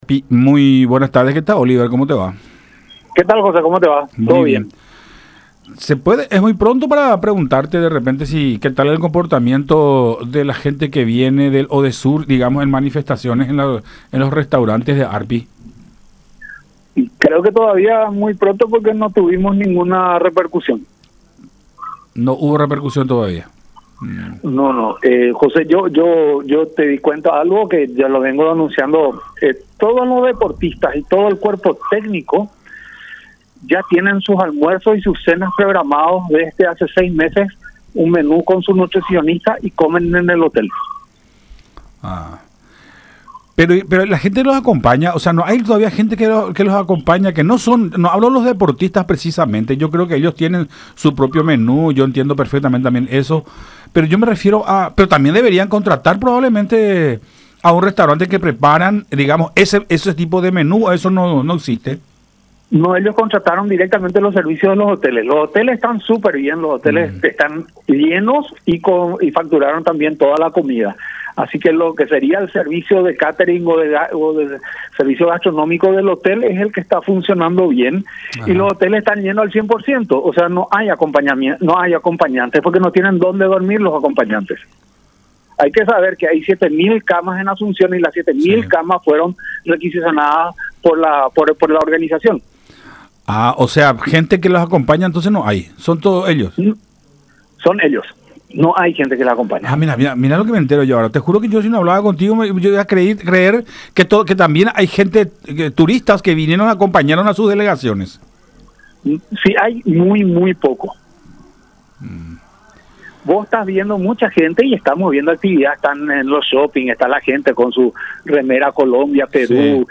en contacto con Buenas Tardes La Unión por Unión TV y radio La Unión